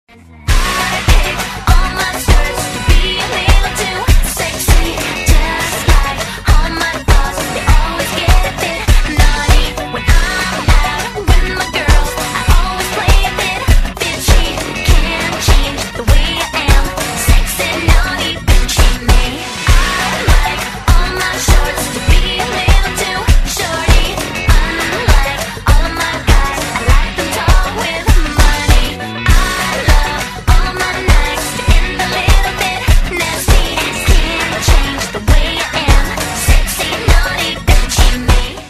分类: MP3铃声